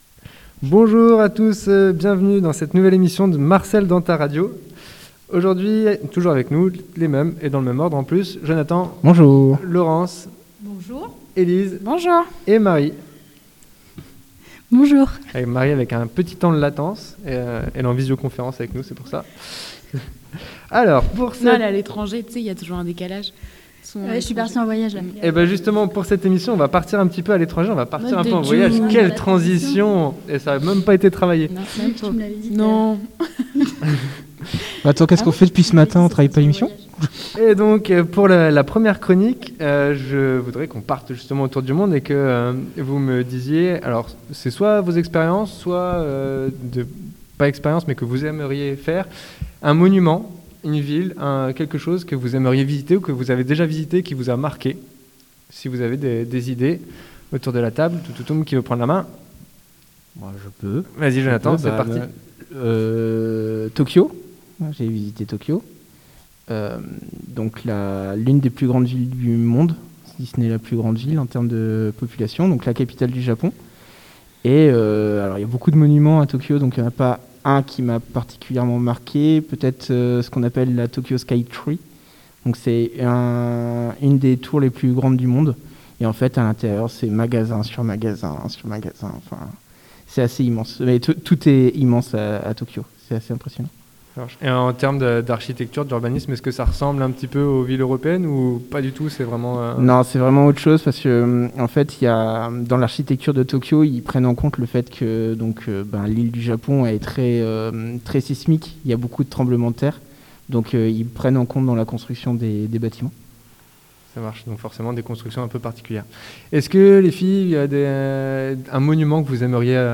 L'équipe d'animateurs du centre social vous propose une émission spéciale "voyage et urbanisme". Vous y écouterez aussi des jeux, quizz et anecdotes !